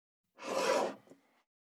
388,机の上をスライドさせる,スー,ツー,サッ,シュッ,スルッ,ズズッ,スッ,コト,トン,ガタ,ゴト,カタ,ザッ,
効果音